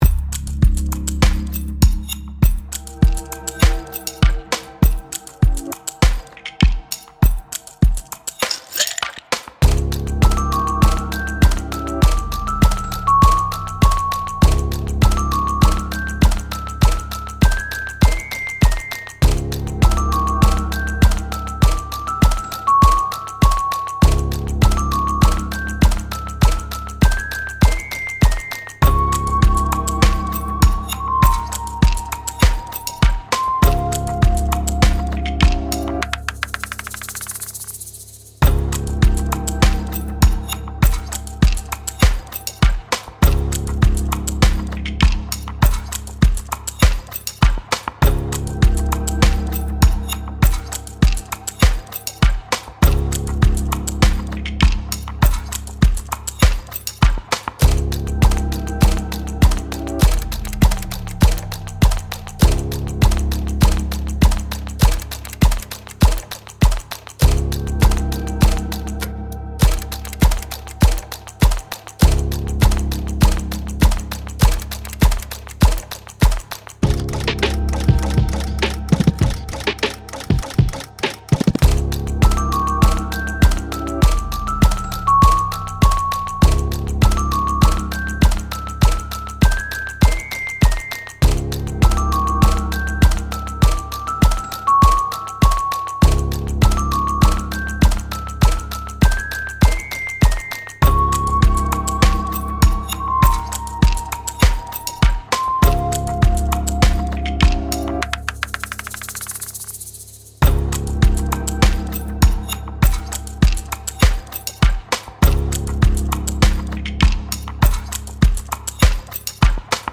時計の針の音をリズムに使用したミステリアスな雰囲気のBGMです。